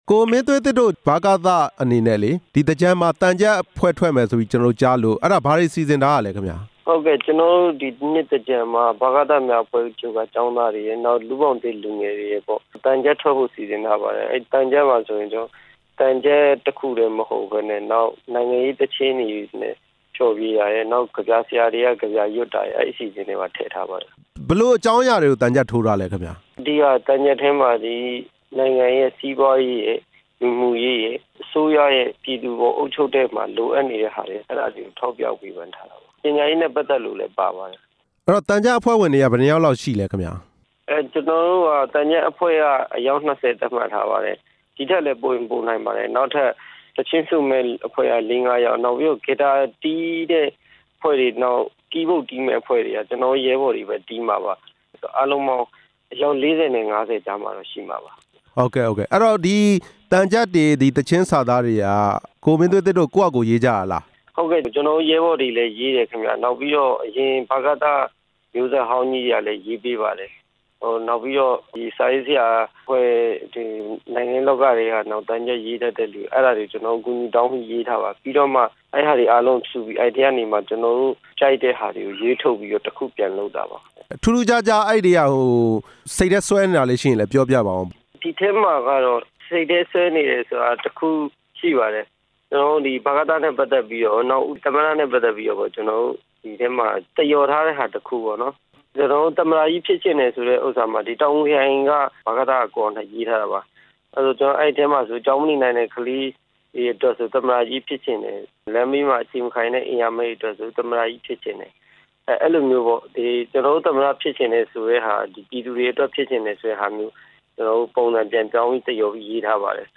ဆက်သွယ်မေးမြန်းထား